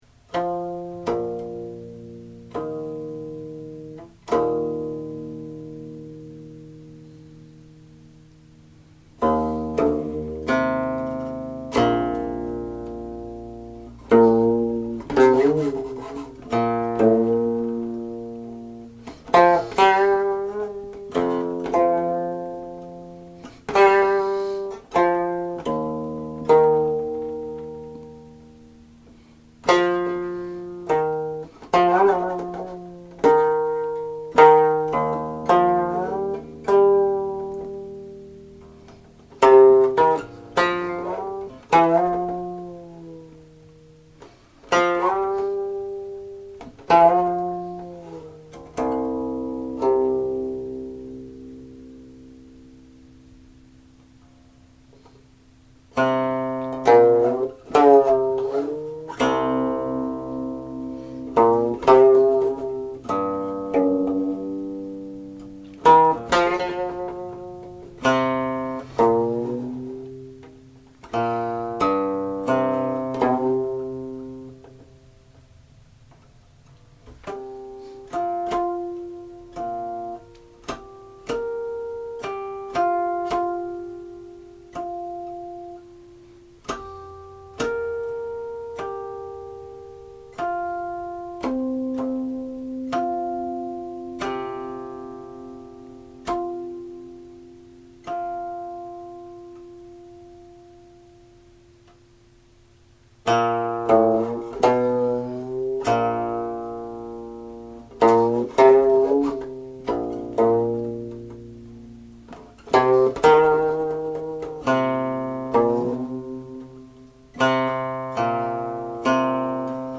Melody and lyrics 14 (lyrics and translation alone; compare Zhu Xi15 )
The setting is largely syllabic.
00.00 Last four notes in harmonics, used as a prelude